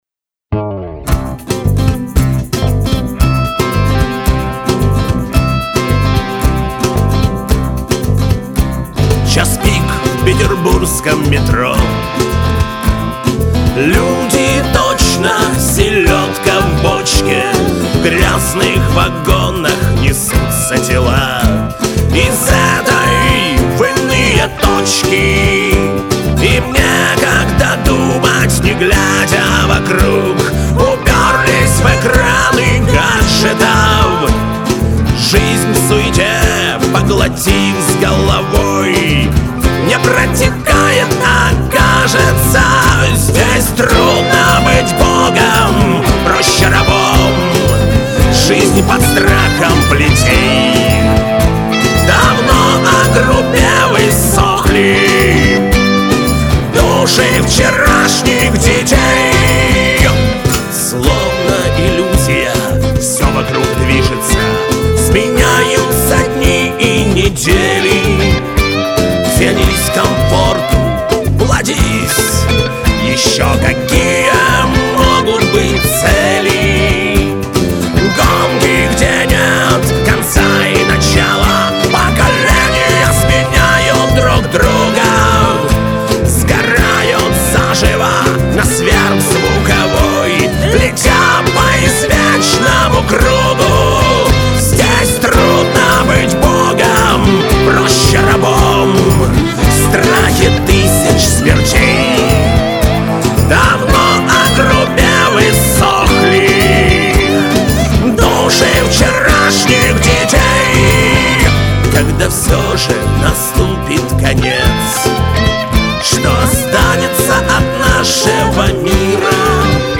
Жанр: панк-рок
вокал, гитары
бэк, перкуссия, скиффл-инструменты.
ритм-гитара.
соло-гитара, бас.
джа-кахон.